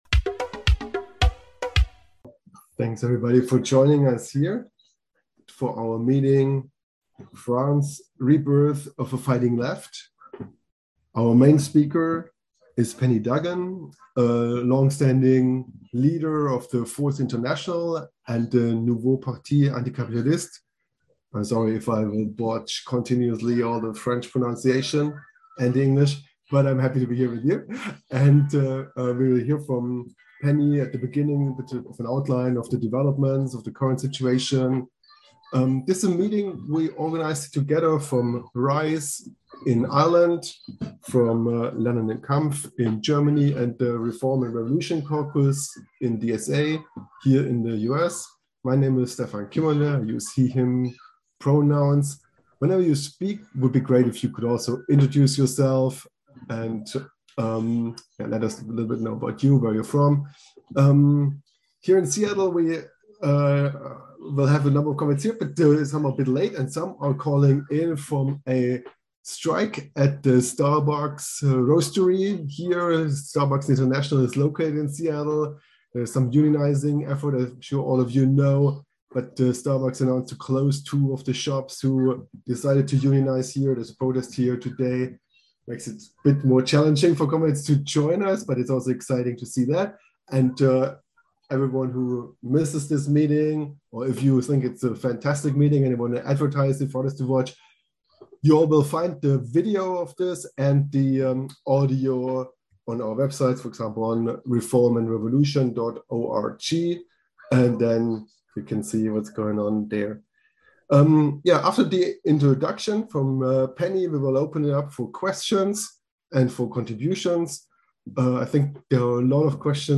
This is the recording of an international meeting on July 17, 2022, where we heard first hand from socialist activists in France and discussed the events.